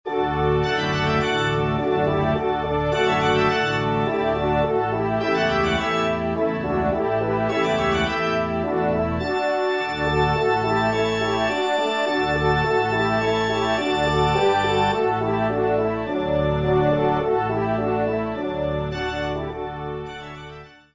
Genre :  Divertissement pour Trompes ou Cors & Orgue
ENSEMBLE